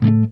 JAZZRAKE 1.wav